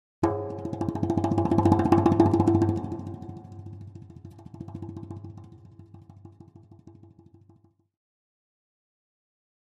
Drums Percussion Danger - Fast Drumming On A Thin Metal Percussion 3